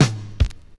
26DR.BREAK.wav